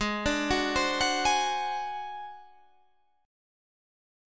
Ab7b5 chord